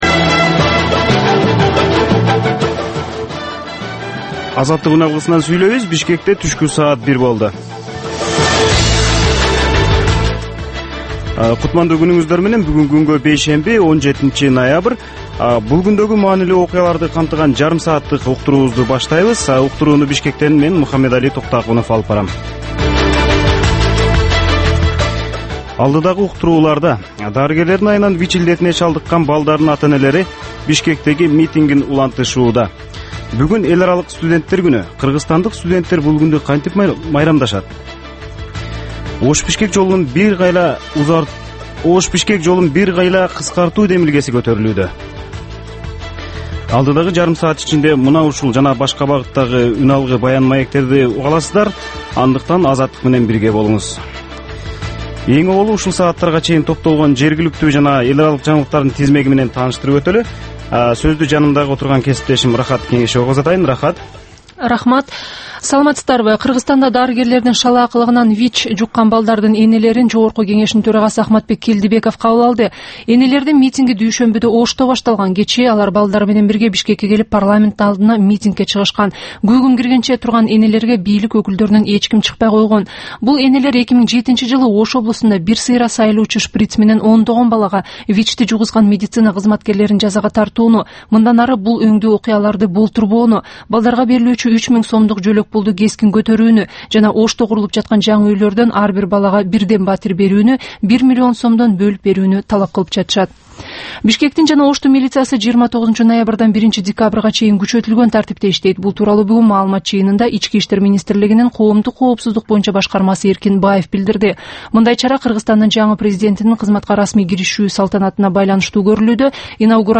"Азаттык үналгысынын" күндөлүк кабарлар топтому Ала-Тоодогу, Борбордук Азиядагы жана дүйнөдөгү эң соңку жаңылыктардан турат. Кабарлардын бул топтому «Азаттык үналгысынын» оригиналдуу берүүсү обого чыккан сааттардын алгачкы беш мүнөтүндө сунушталат.